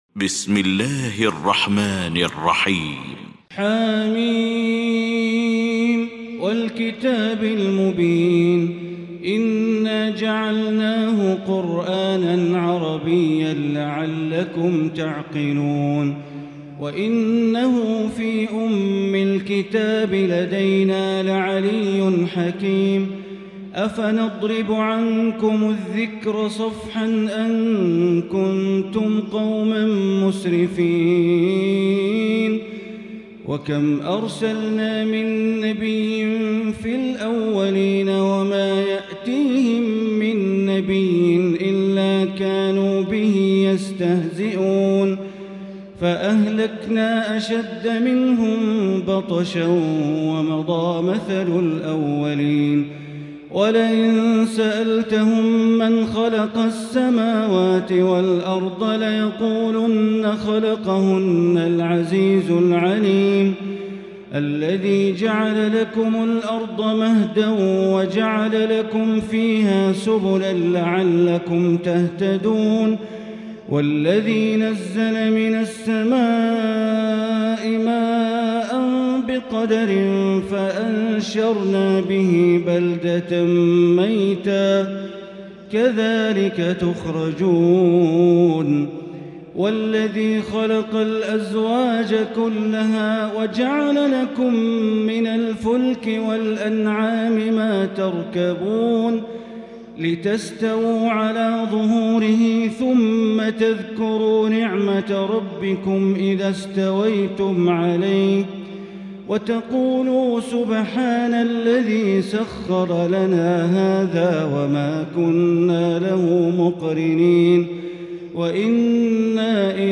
المكان: المسجد الحرام الشيخ: معالي الشيخ أ.د. بندر بليلة معالي الشيخ أ.د. بندر بليلة الزخرف